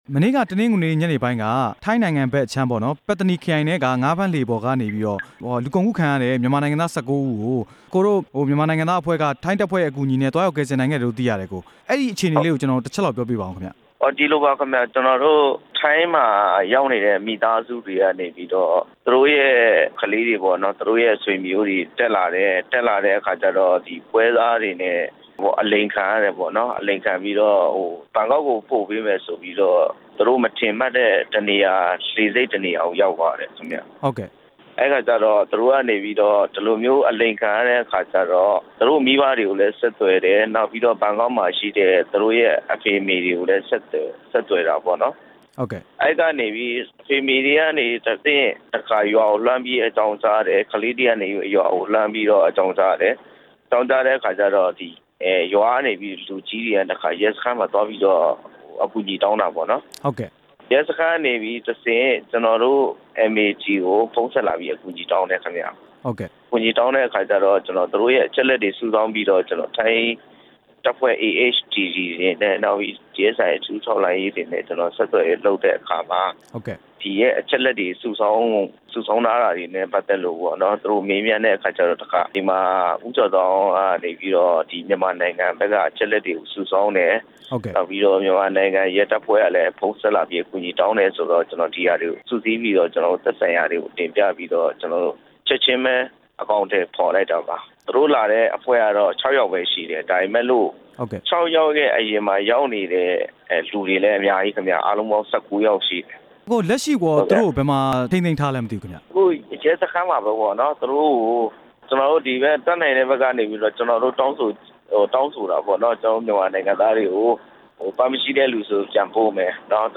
ထိုင်းငါးဖမ်းလှေပေါ်က လူကုန်ကူးခံရတဲ့ မြန်မာနိုင်ငံသားတွေအကြောင်း မေးမြန်းချက်